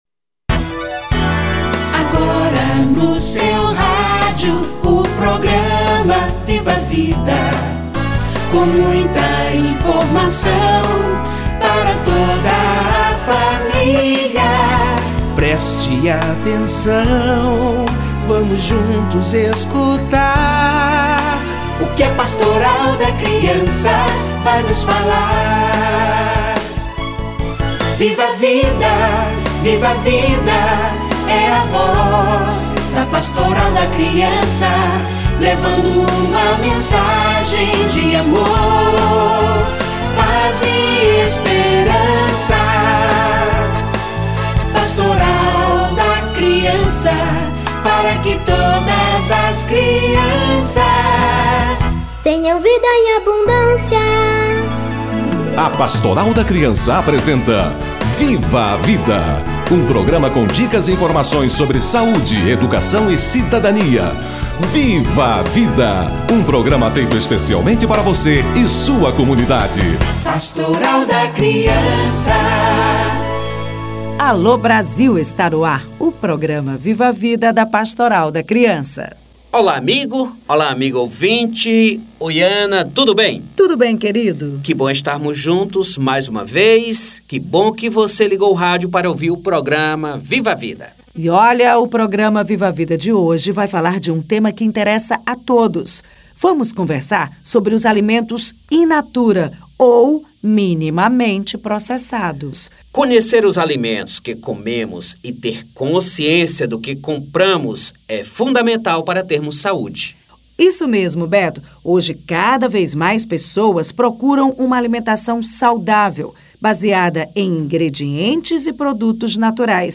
Rotulagem de alimentos - Entrevista